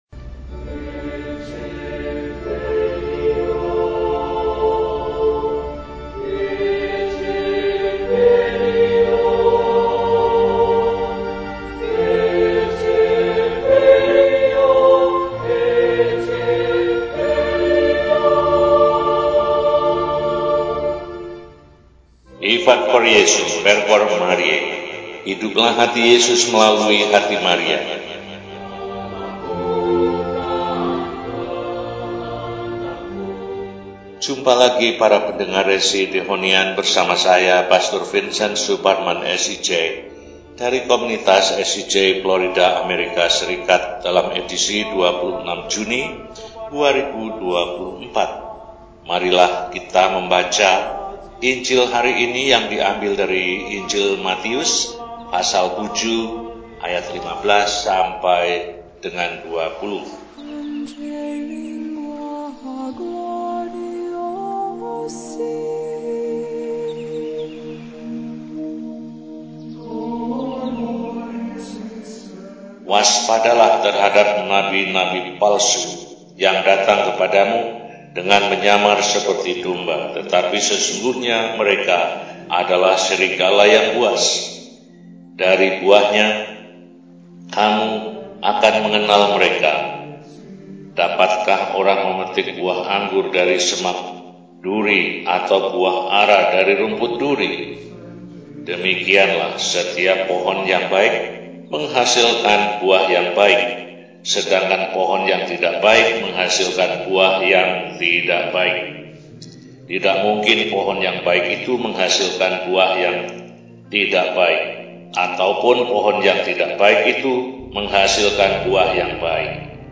Rabu, 26 Juni 2024 – Hari Biasa Pekan XII – RESI (Renungan Singkat) DEHONIAN